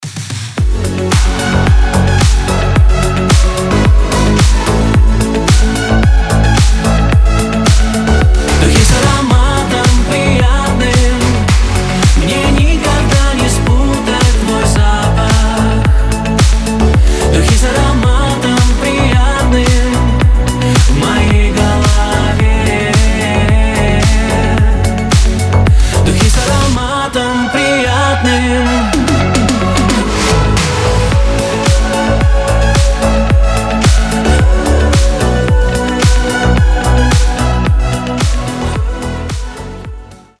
Танцевальные рингтоны
Мужской голос , Мелодичные